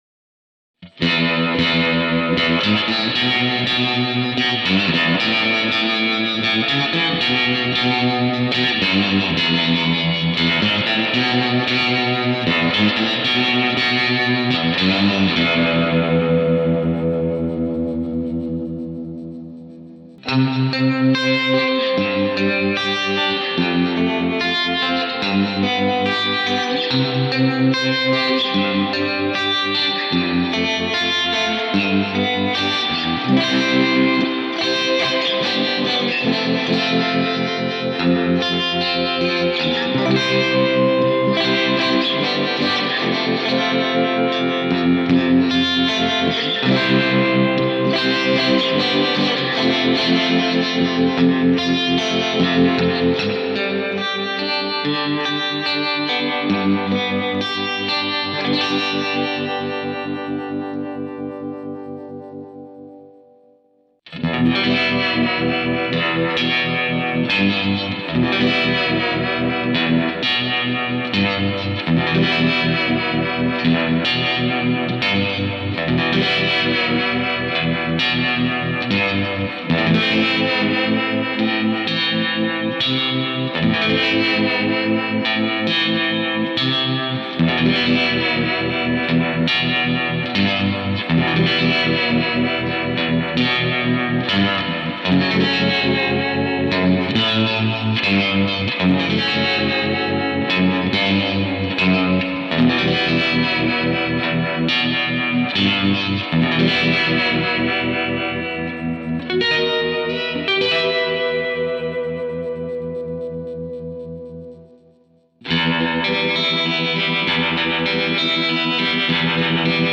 Harmonic tremolo - needs tweaking
Try to tune out the reverb if you can.